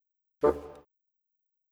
Modern 26 Bassoon 01.wav